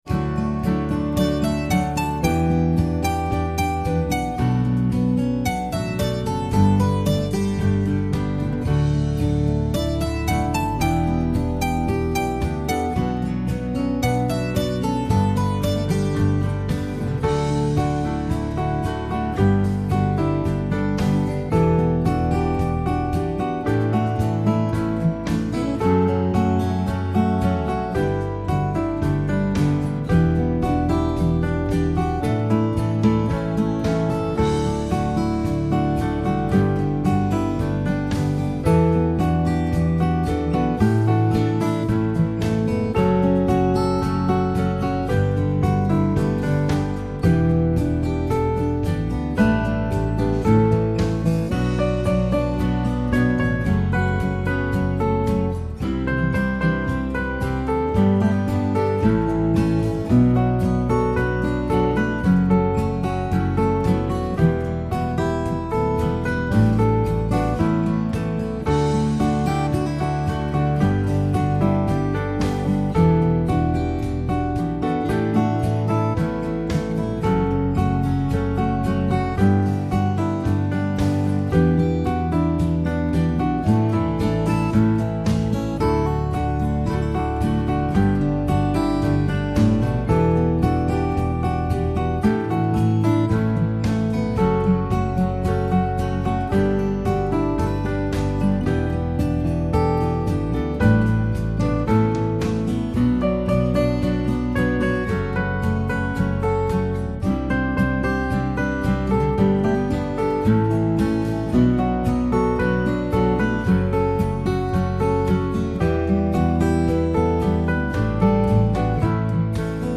I think it would be better thought of as a gentle P&W song.